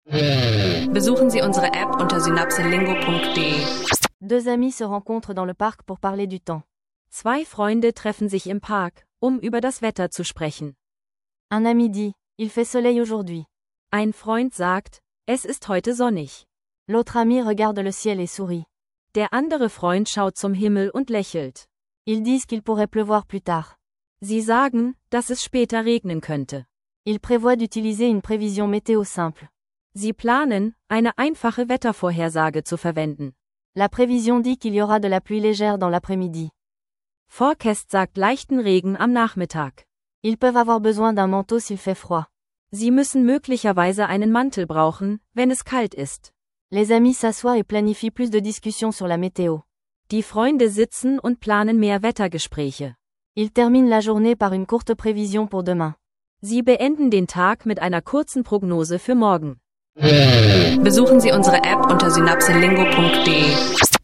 In dieser Folge üben zwei Freunde grundlegende